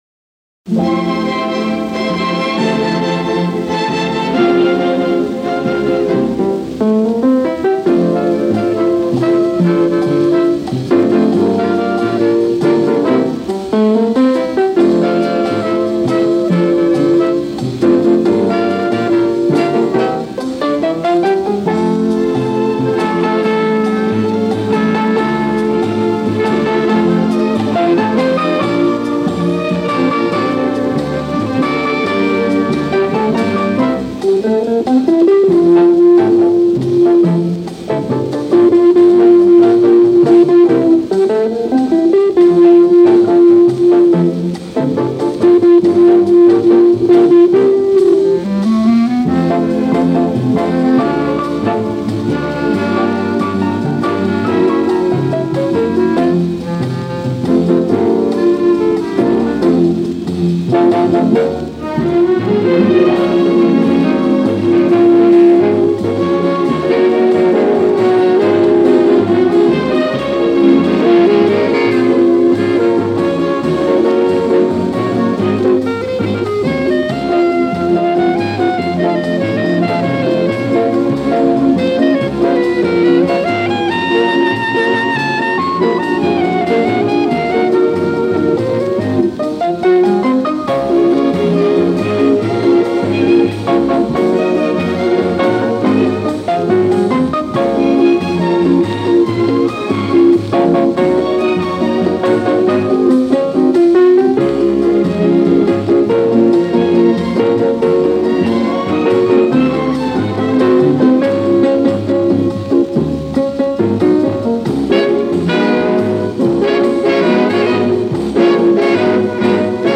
Но всё-таки шипение попытался свести к минимуму: